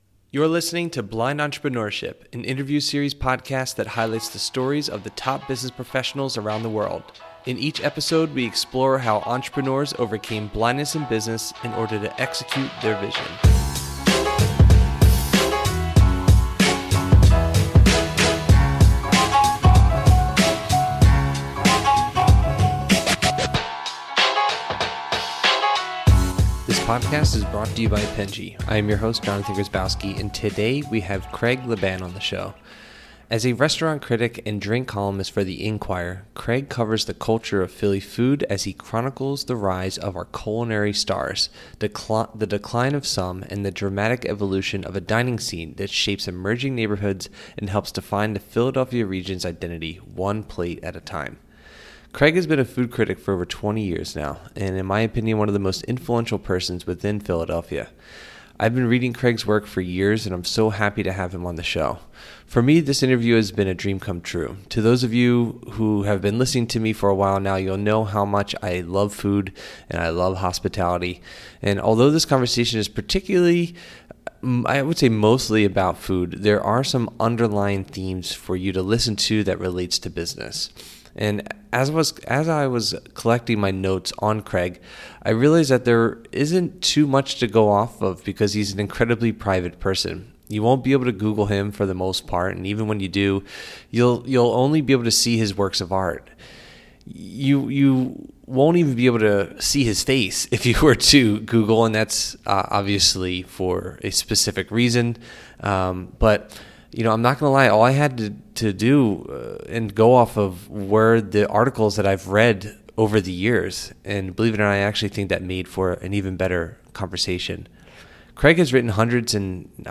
For me, this interview has been a dream come true.